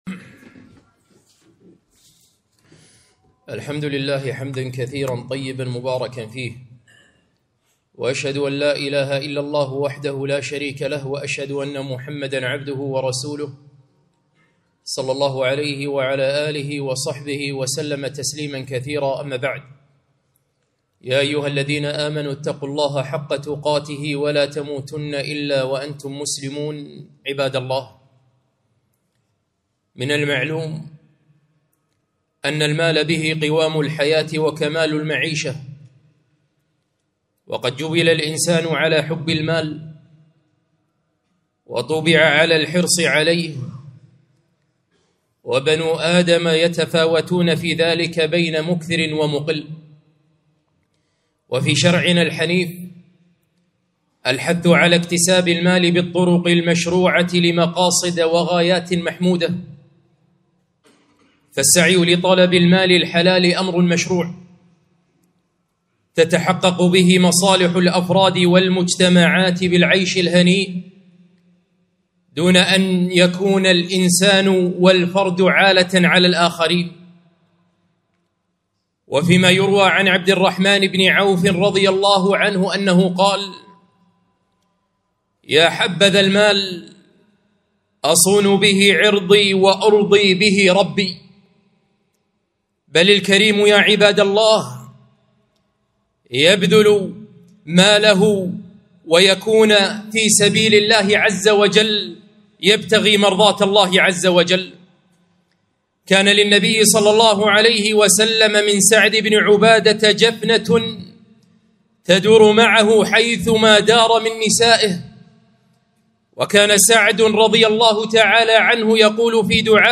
خطبة - اللقمة الحلال